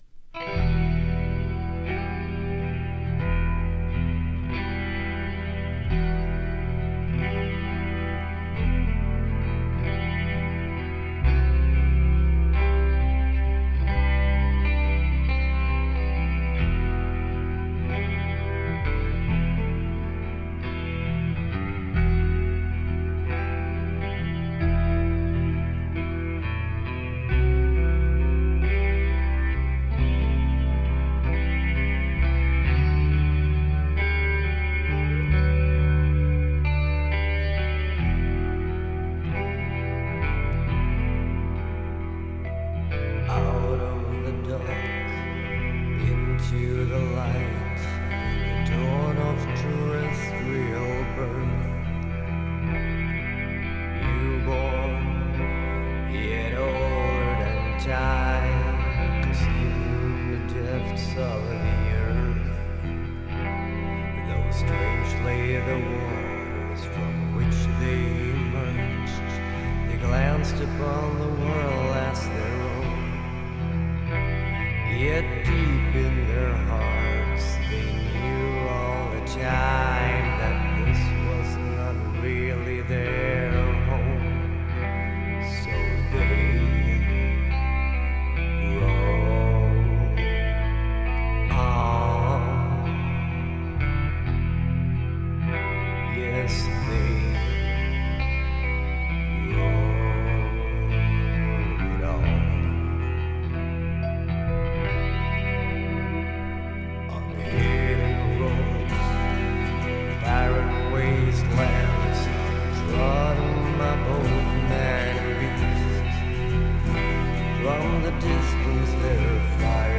کی گفته بلک متال حتما باید وحشی و خشن باشه!
black metal